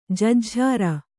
♪ jujjhāra